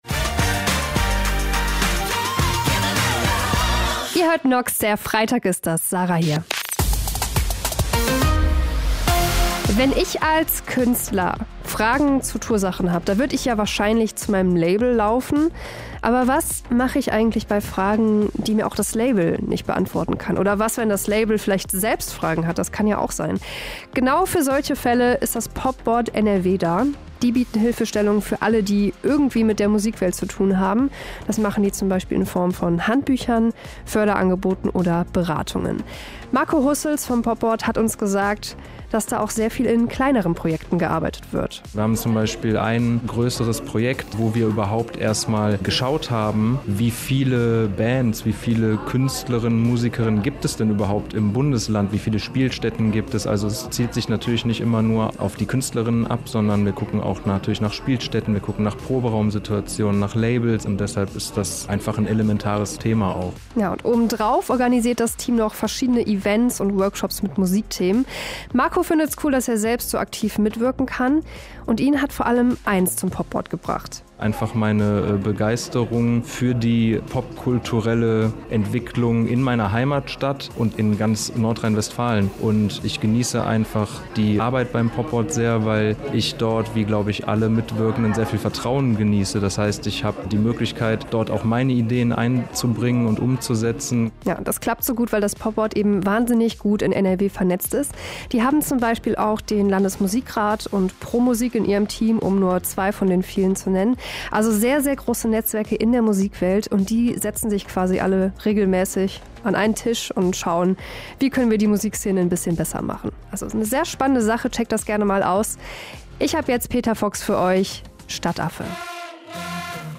Radiobeitrag "PopBoard NRW" - NOXX Radio